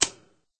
clunk_1.ogg